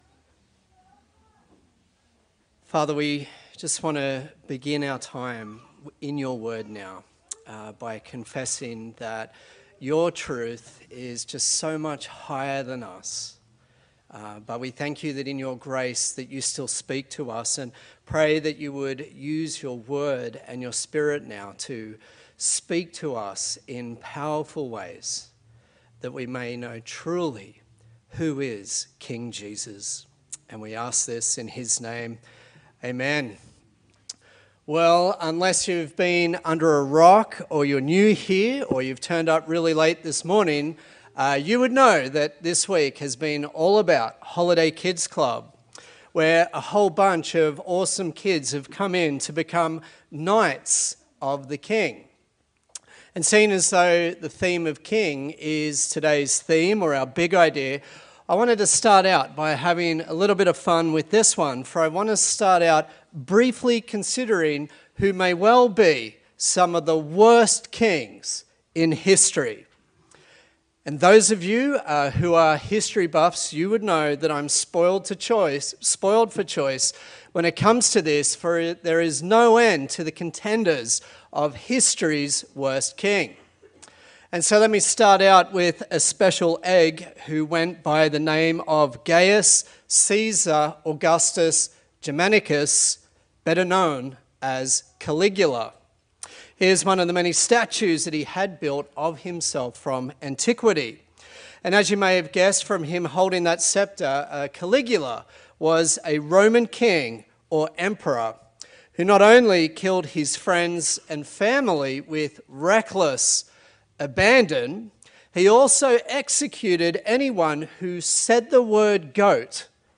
A sermon on the Gospel of John
Service Type: Sunday Service